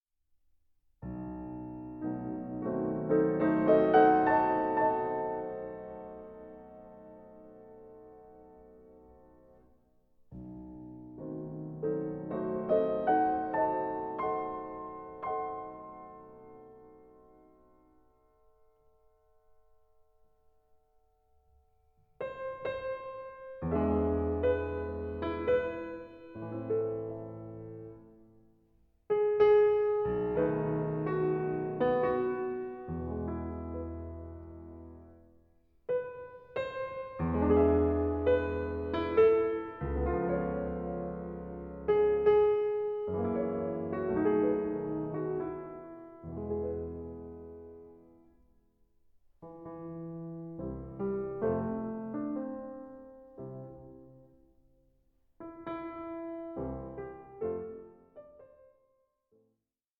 INTROSPECTIVE PIECES ABOUT LOVE IN ALL ITS MANIFESTATIONS